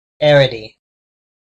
Ääntäminen
Synonyymit rank type adinity adicity Ääntäminen US Tuntematon aksentti: IPA : /ˈæɹɪɾij/ IPA : /ˈeəɹɪɾij/ Haettu sana löytyi näillä lähdekielillä: englanti Käännös Konteksti Substantiivit 1.